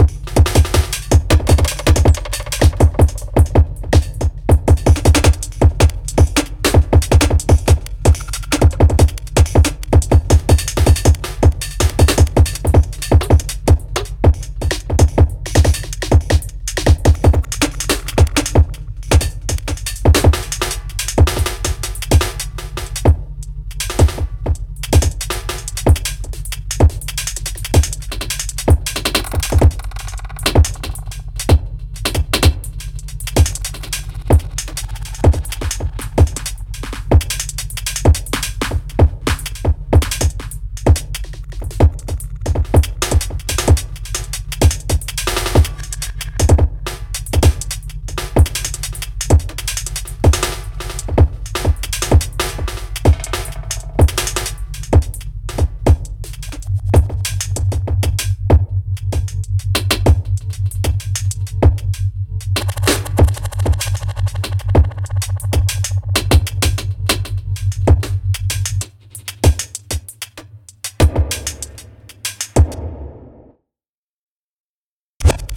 breakbeat.mp3